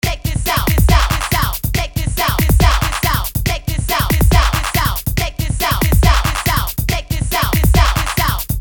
How about a hip hop beat?
Okay, click and drag "HIP_Hat", "HIP_Kick_4" and "HIP_Snare_7" to the Sound channels.